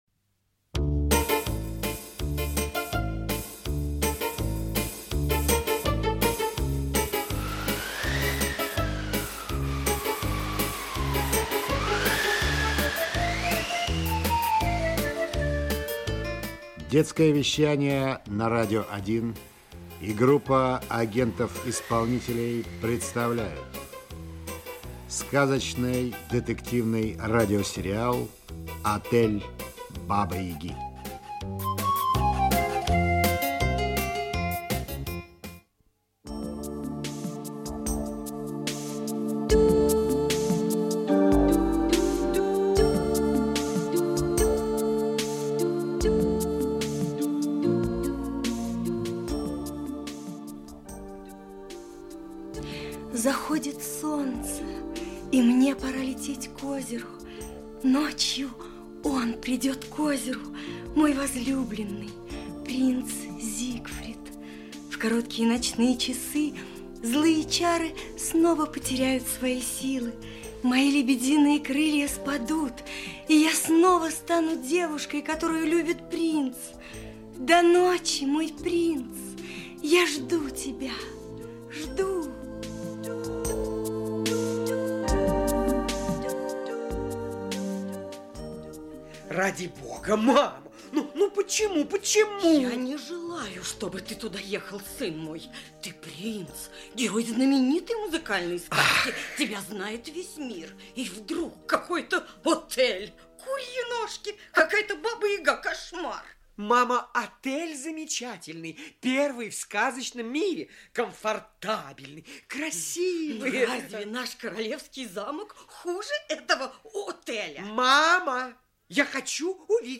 Аудиокнига Отель Бабы Яги. "Колдовское зелье". Часть 1 | Библиотека аудиокниг